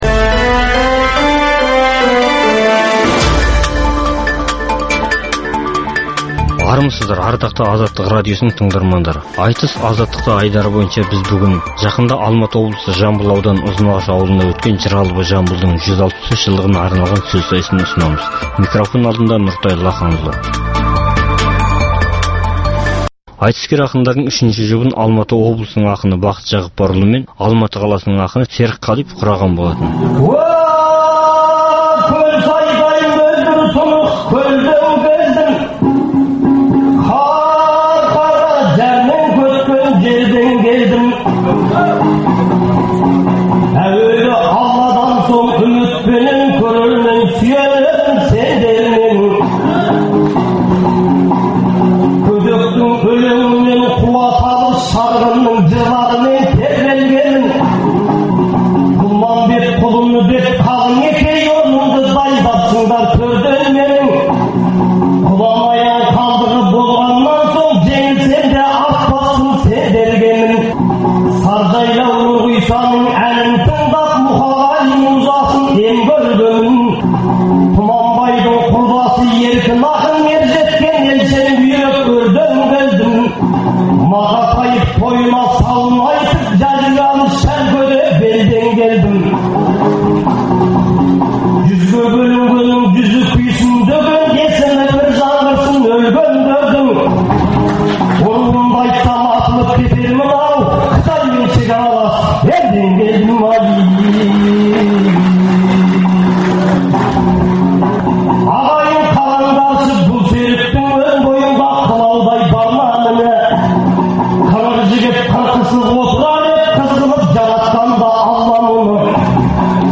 Айтыс - Азаттықта
Жақында жыр алыбы - Жамбылдың 165 жылдығына арналып, Алматы облысының Ұзынағаш ауданында өткен ақындар айтысына қатысқан ақындардың жыр сайысымен таныстыруымызды әлі қарай жалғастырамыз.